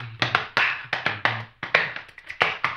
HAMBONE 04-L.wav